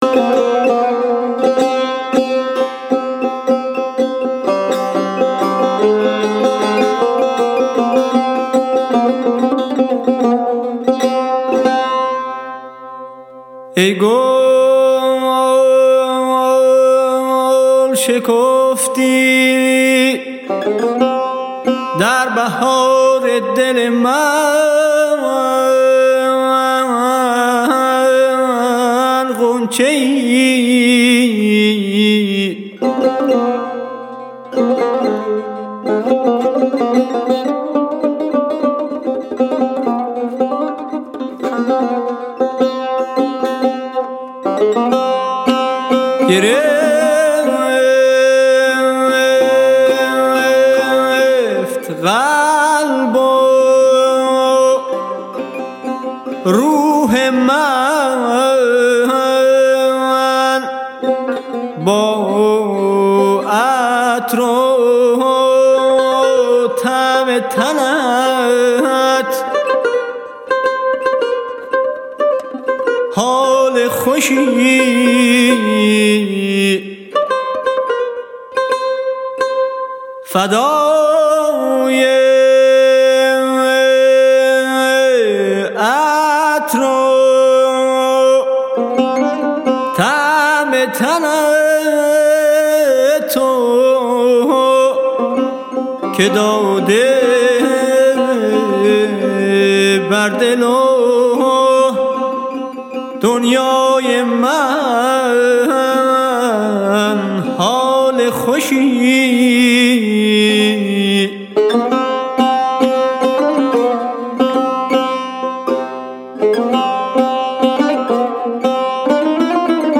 تار
تک اهنگ ایرانی